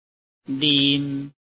臺灣客語拼音學習網-客語聽讀拼-海陸腔-鼻尾韻
拼音查詢：【海陸腔】lim ~請點選不同聲調拼音聽聽看!(例字漢字部分屬參考性質)